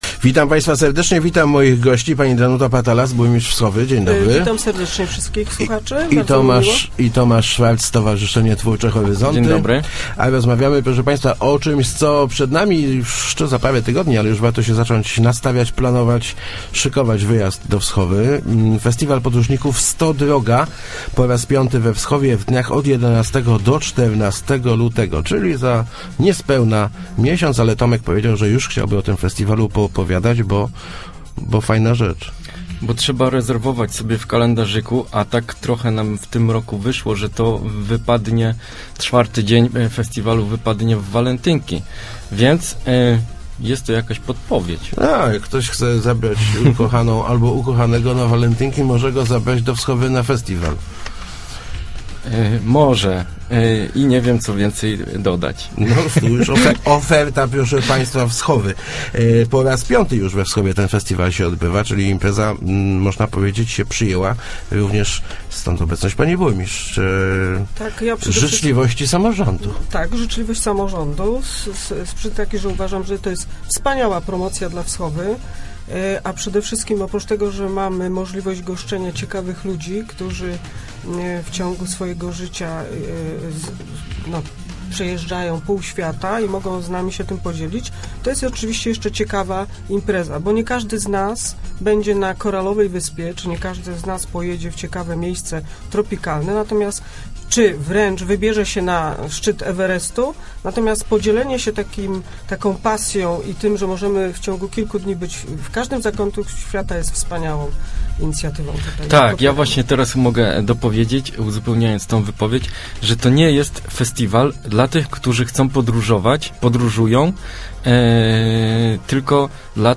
Start arrow Rozmowy Elki arrow Jubileuszowy festiwal podróżników